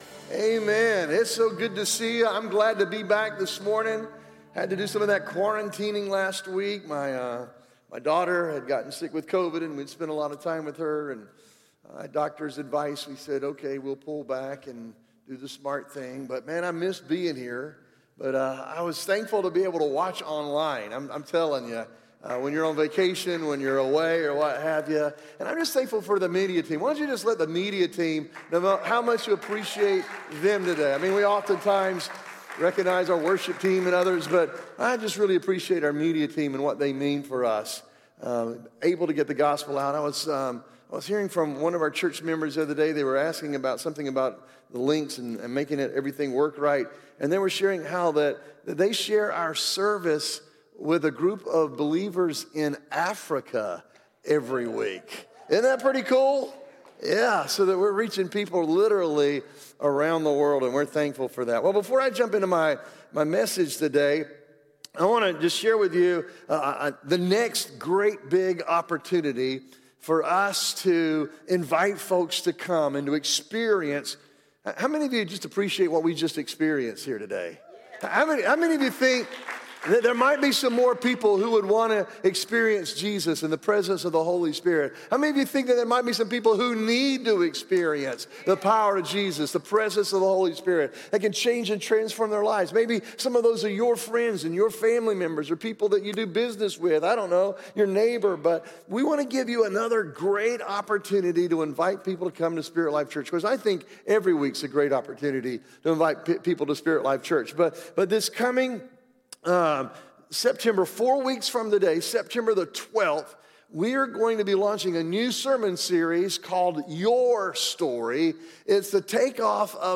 Sermons | SpiritLife Church